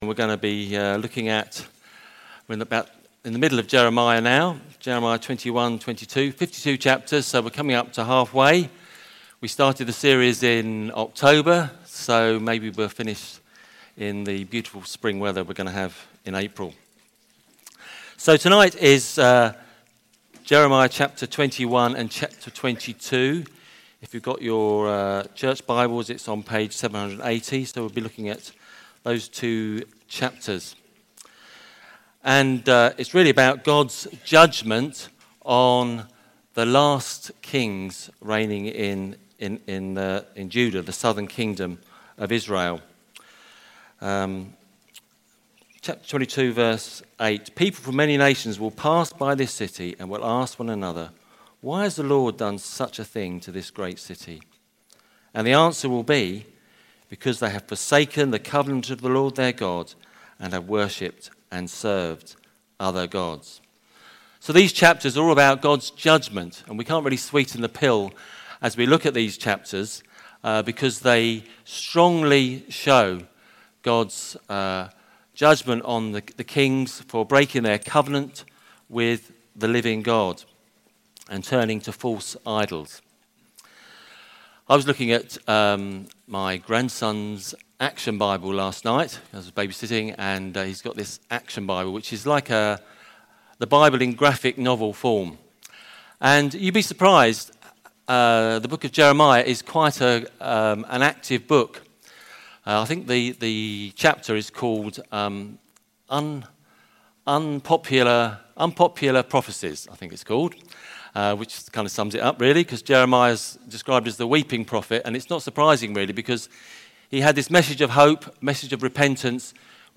Back to Sermons Your kings and God’s king